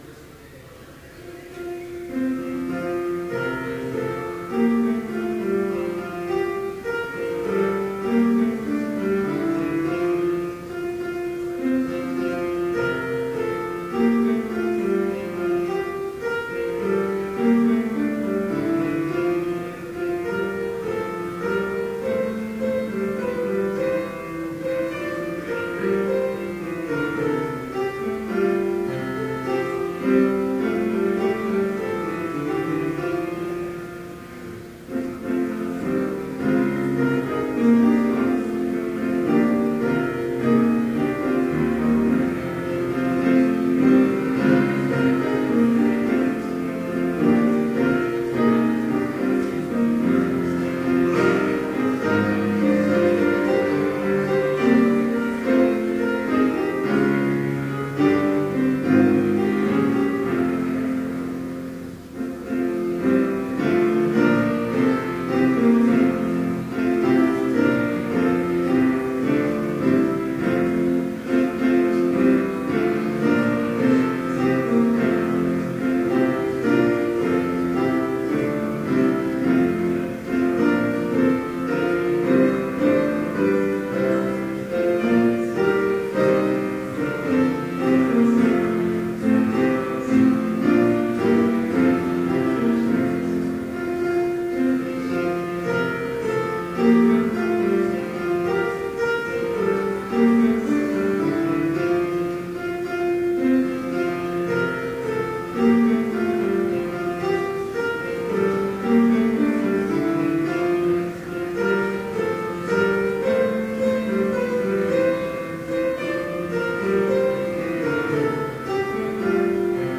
Complete service audio for Chapel - October 31, 2012